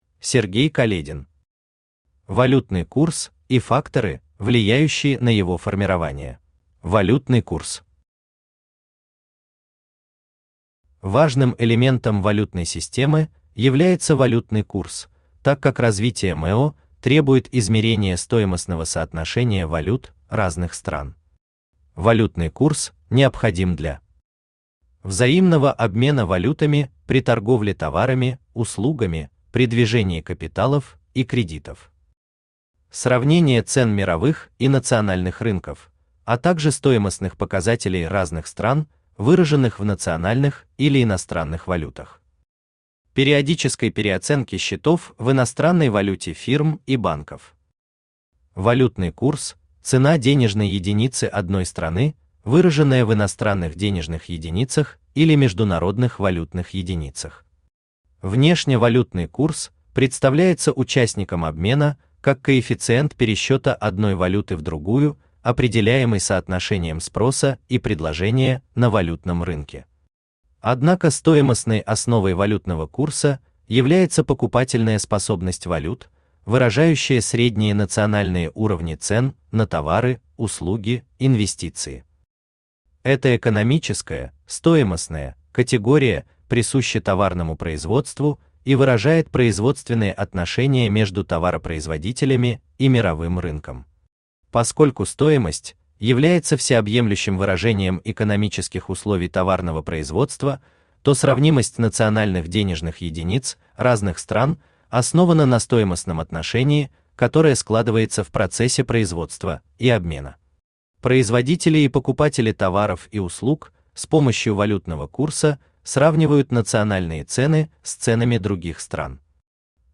Аудиокнига Валютный курс и факторы, влияющие на его формирование | Библиотека аудиокниг
Aудиокнига Валютный курс и факторы, влияющие на его формирование Автор Сергей Каледин Читает аудиокнигу Авточтец ЛитРес.